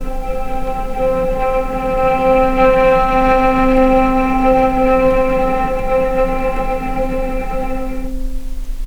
vc-C4-pp.AIF